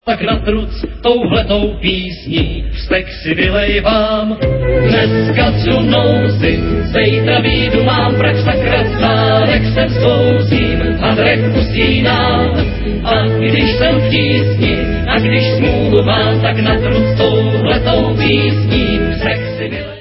Renesančně laděnými autorskými písněmi